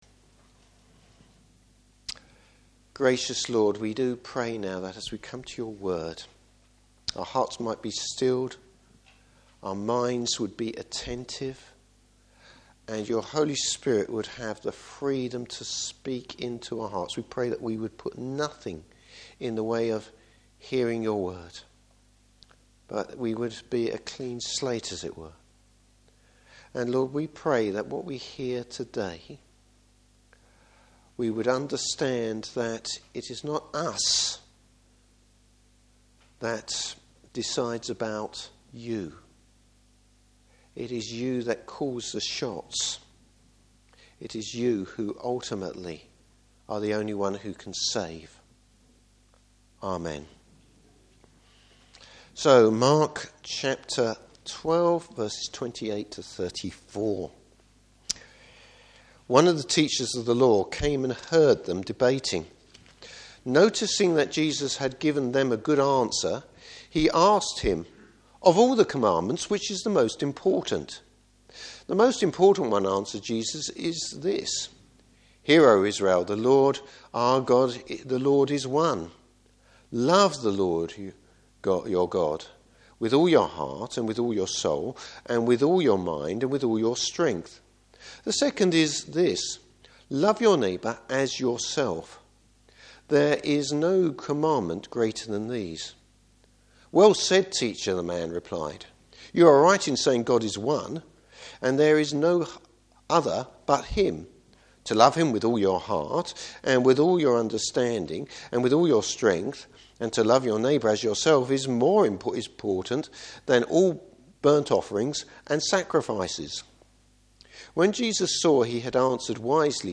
Service Type: Morning Service A teacher of the Law gets a surprise.